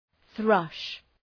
Προφορά
{ɵrʌʃ}